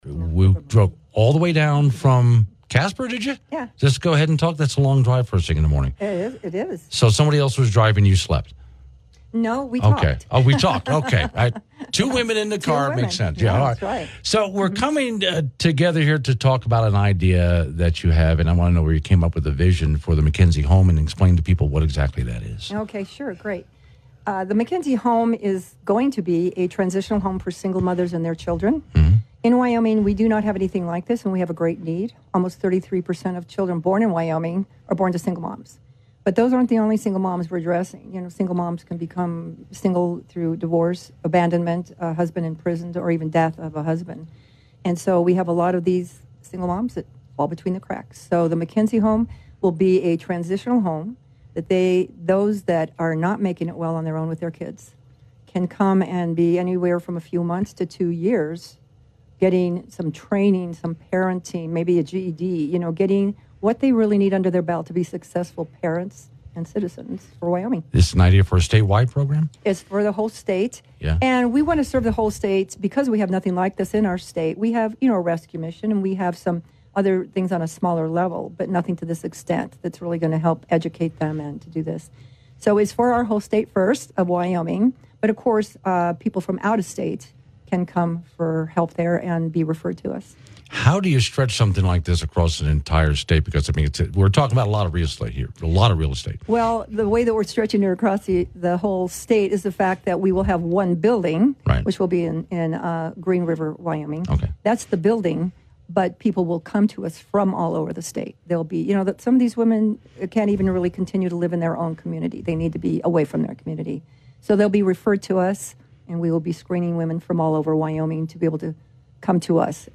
You can listen to the radio interview below.